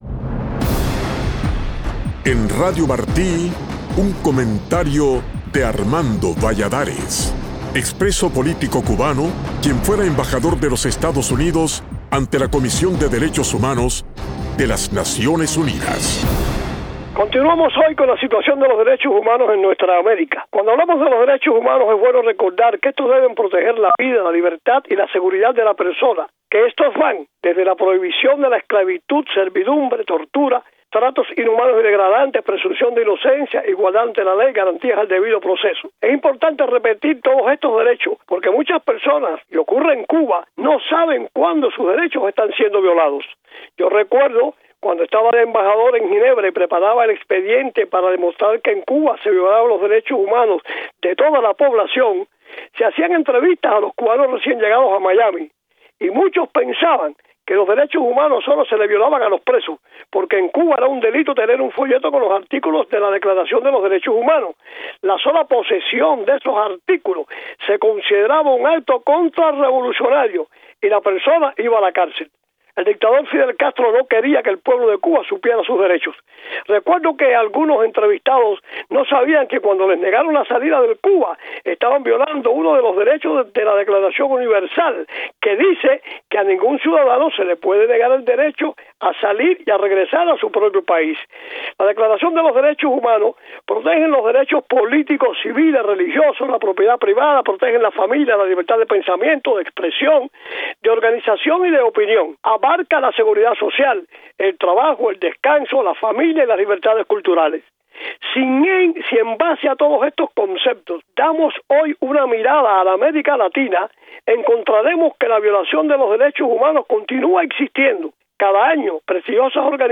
Es la realidad de Cuba y otros países de nuestra América: muchas personas no saben cuándo sus derechos están siendo violados, por eso es importante reiterarlos, señala en su comentario de hoy el exembajador de EEUU ante ONU, Armando Valladares.